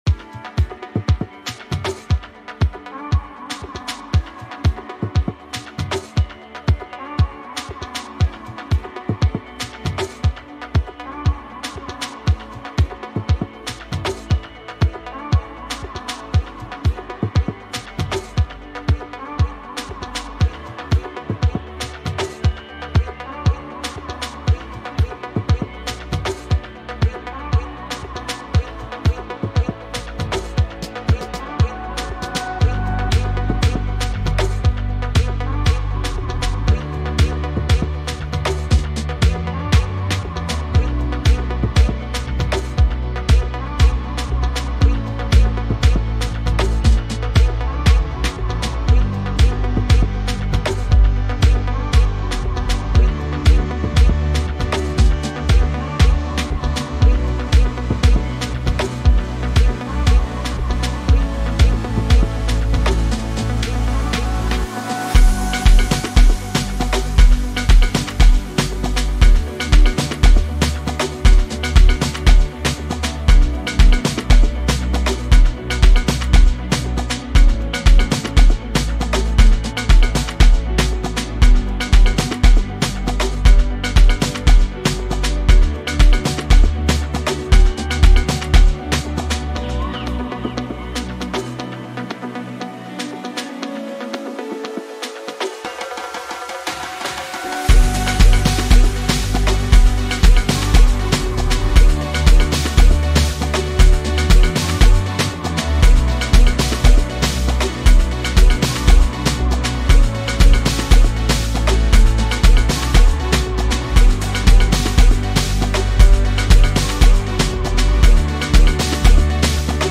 Home » Amapiano
trendy and latest Amapiano track